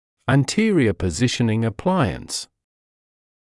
[æn’tɪərɪə pə’zɪʃnɪŋ dɪ’vaɪs][эт’тиэриэ пэ’зишнин ди’вайс]переднее позиционирующее устройство, позиционирующий сплинт для переднего отдела